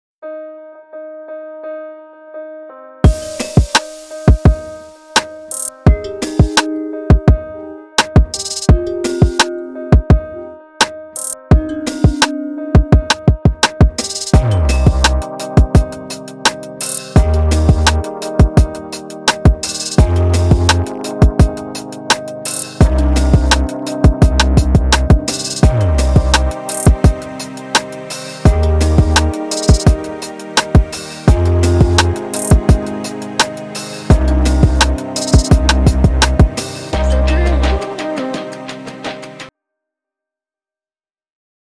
BASE MUSICAL